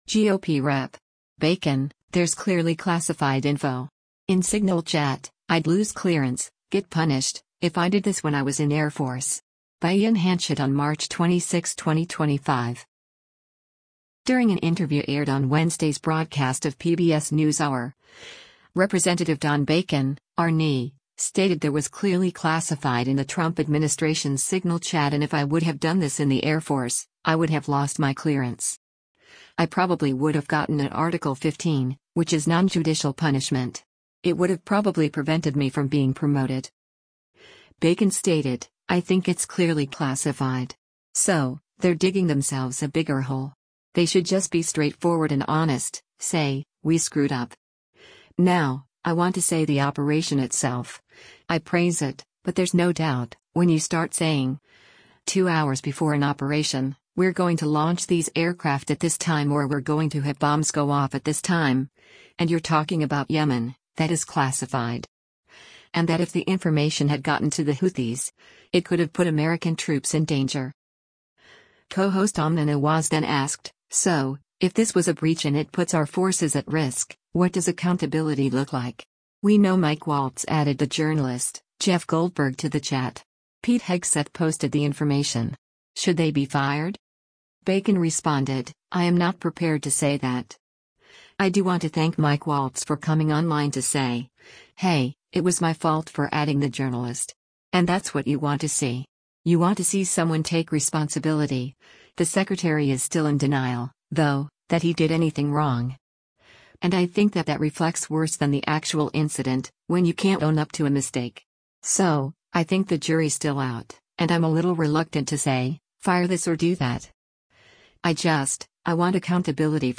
During an interview aired on Wednesday’s broadcast of “PBS NewsHour,” Rep. Don Bacon (R-NE) stated there was “clearly classified” in the Trump administration’s Signal chat and “If I would have done this in the Air Force, I would have lost my clearance. I probably would have gotten an Article 15, which is nonjudicial punishment. It would have probably prevented me from being promoted.”